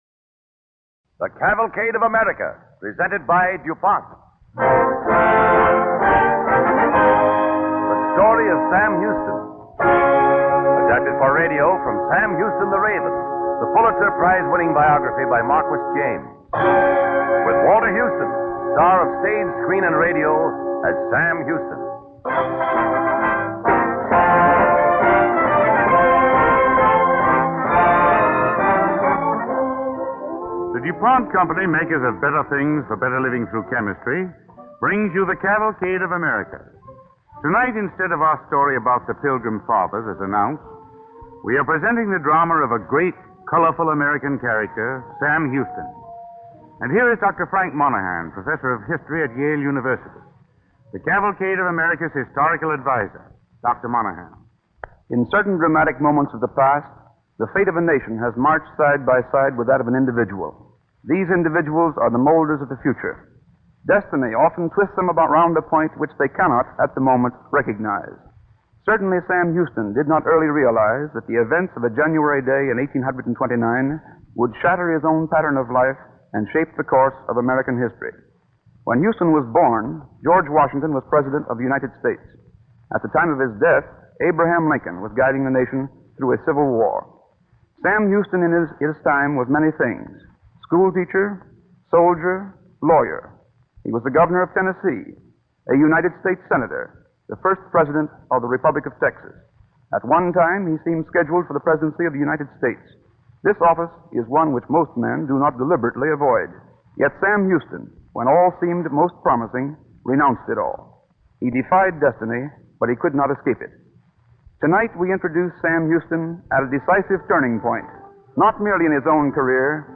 The Story of Sam Houston, starring Walter Houston
With announcer